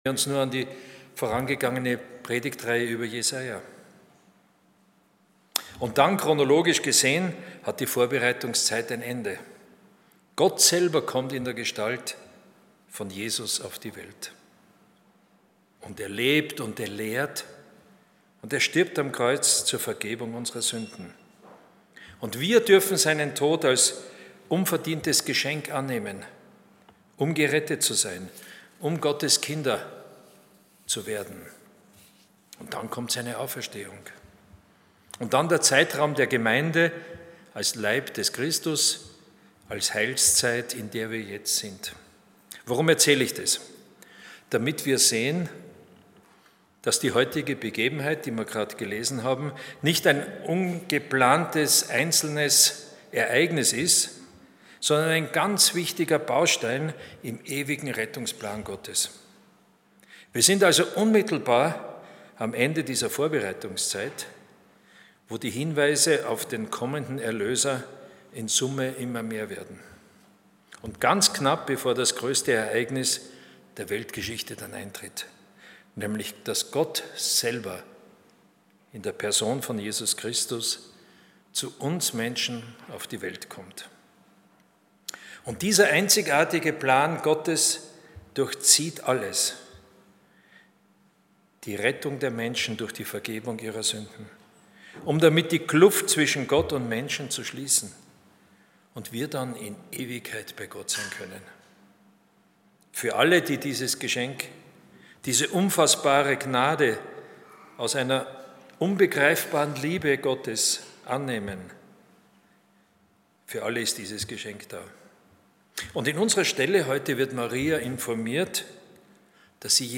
Predigten